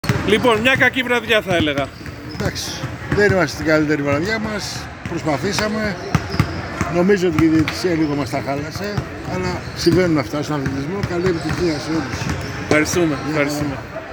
Δηλώσεις Πρωταγωνιστών: